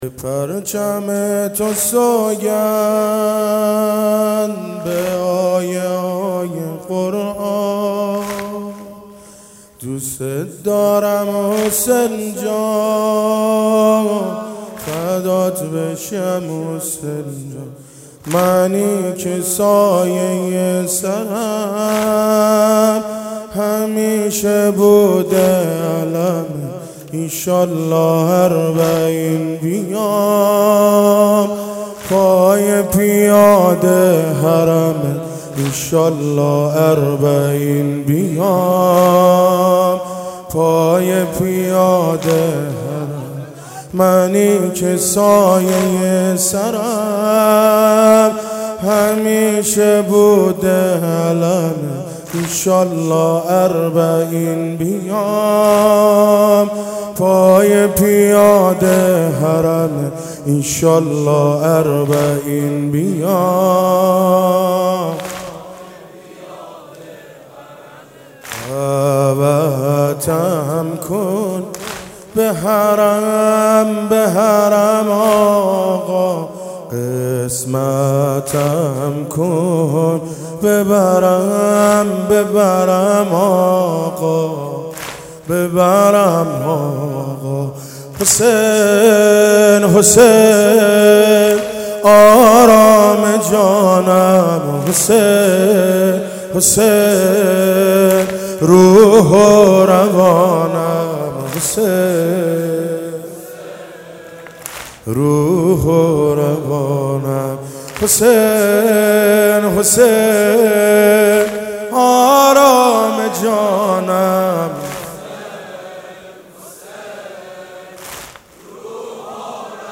واحد زیبا/ به پرچم تو سوگند